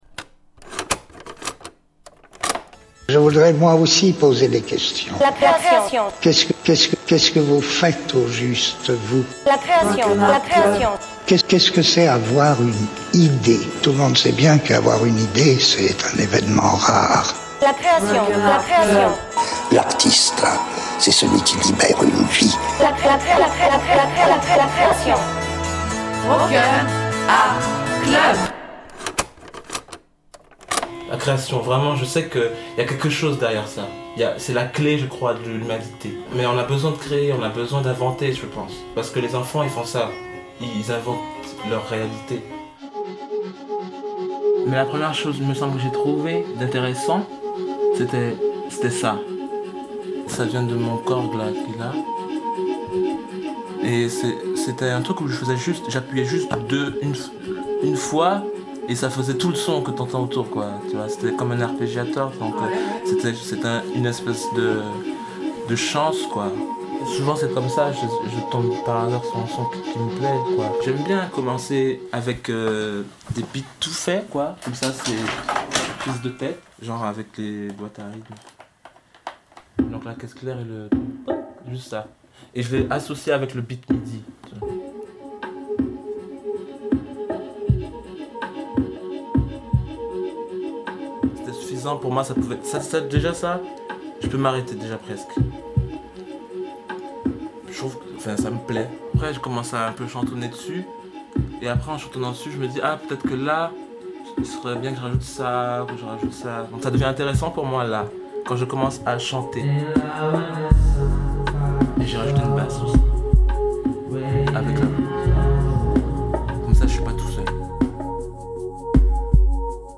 Entre musique astrale et kalimba, c'est probablement l'épisode qui t'en apprendra le plus sur la vie.